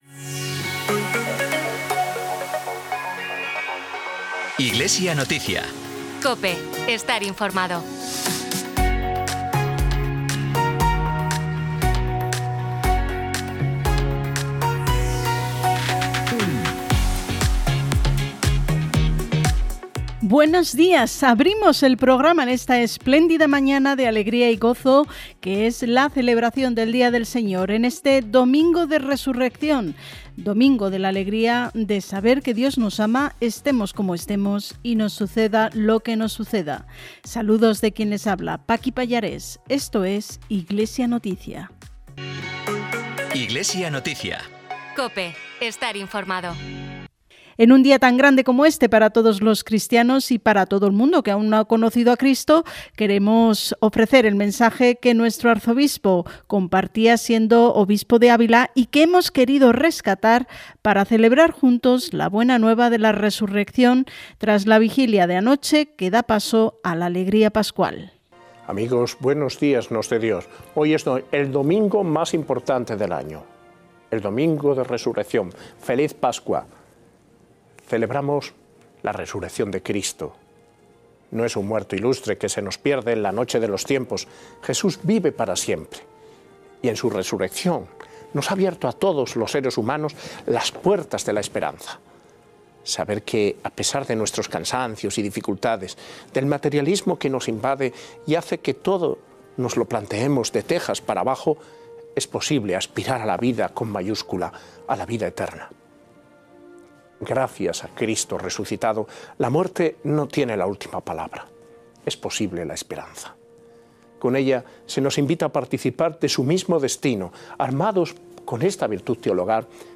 Programa emitido en COPE Granada y COPE Motril el 20 de abril de 2025.